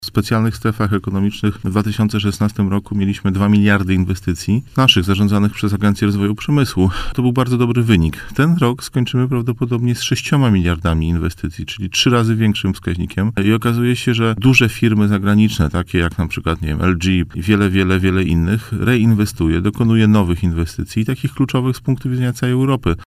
– Zmienia się przede wszystkim rola Polski w europejskim przemyśle – powiedział w poranku „Siódma9” na antenie Radia Warszawa prezes Agencji Rozwoju Przemysłu Marcin Chludziński.